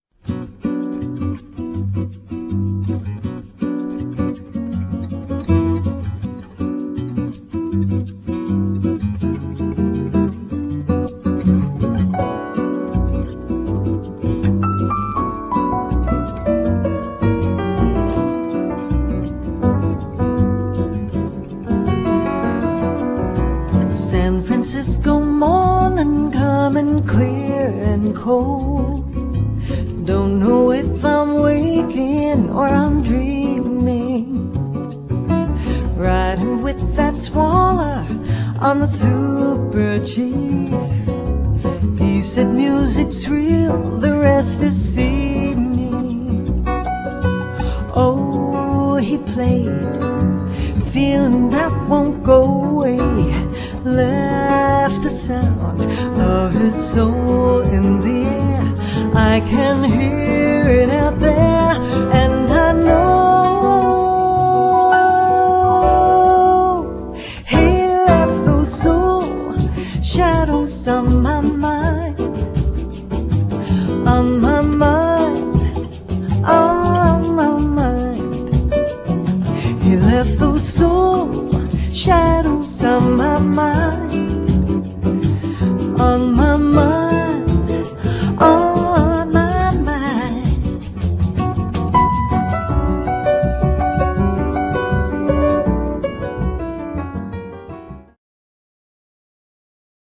Jazz na CD
vocals